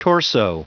Prononciation du mot torso en anglais (fichier audio)
Prononciation du mot : torso